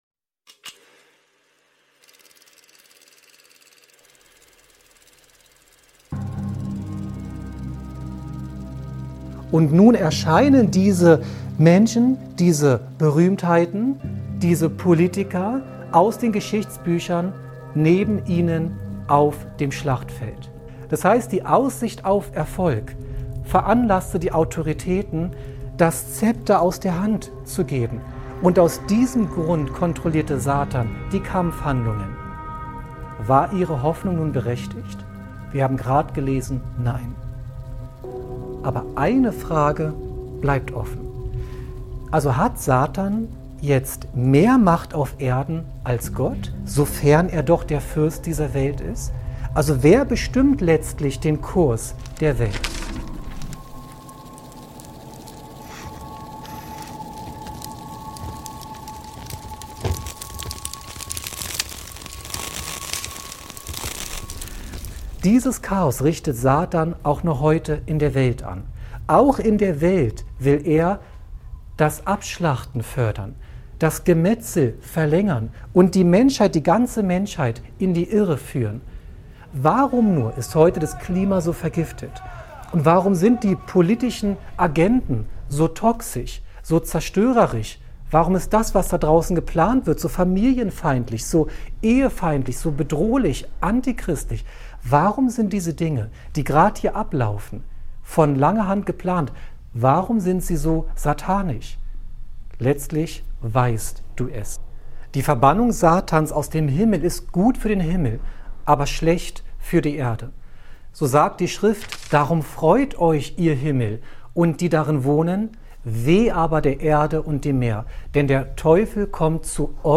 In diesem fesselnden Vortrag wird das Verhältnis zwischen Gott und Satan ergründet. Die Manipulation der Menschen und deren Freiheit zur Wahl zwischen Gut und Böse stehen im Fokus.